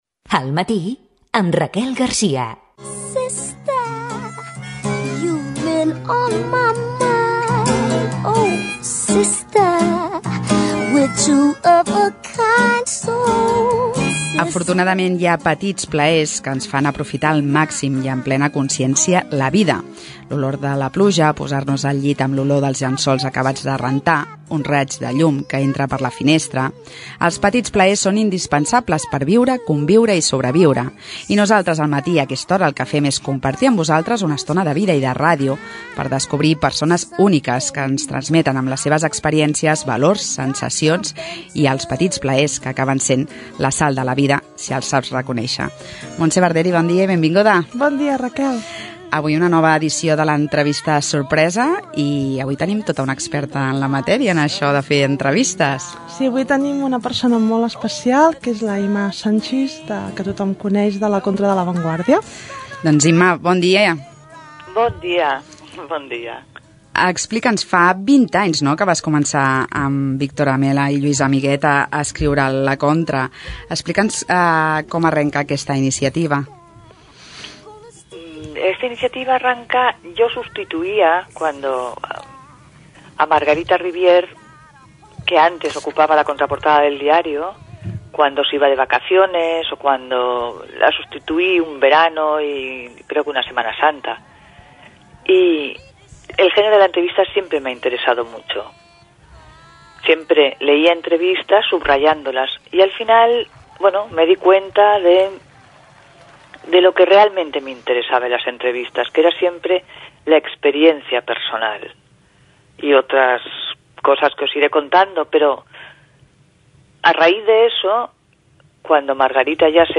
Al matí: entrevista sorpresa - Ràdio Sabadell, 2018
Àudios: arxiu sonor de Ràdio Sabadell
RÖdio-Sabadell-Al-Mat°-entrevista-sorpresa.mp3